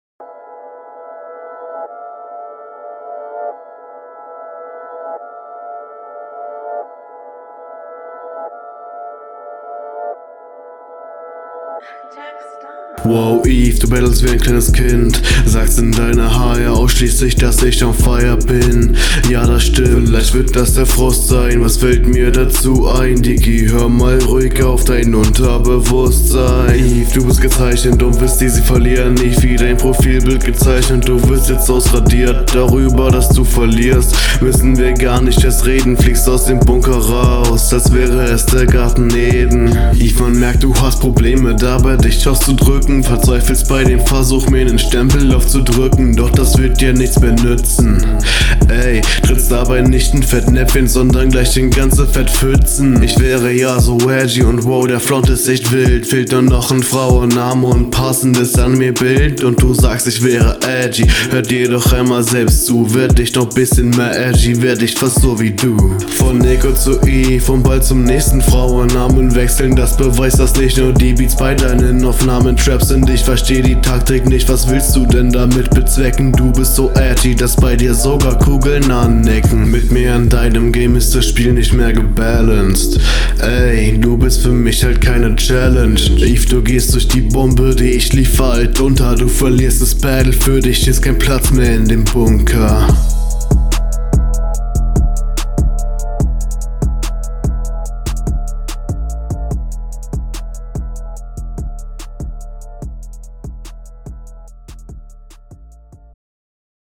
Beat ist cool.
Einstieg kurz off-beat.
Flow: Da sind n paar verhaspler und Nuschler Drinnen.